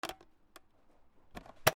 ポットのふたを開ける